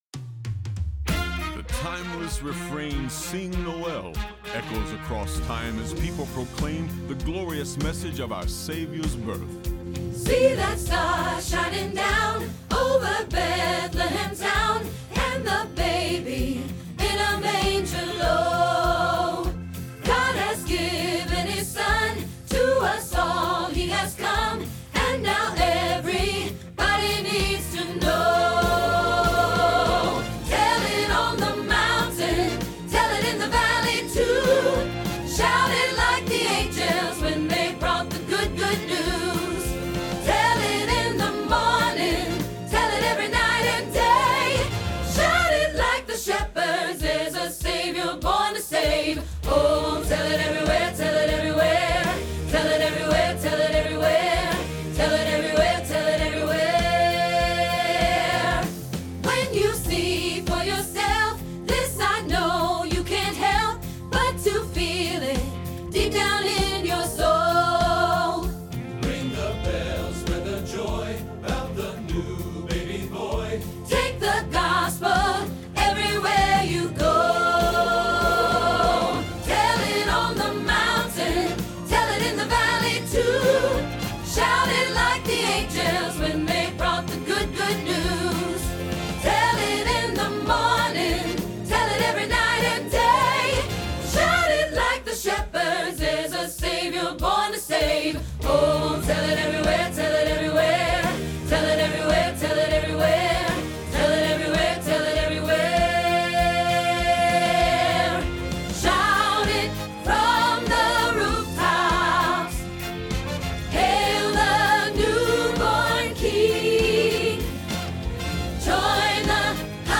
Soprano Practice Trax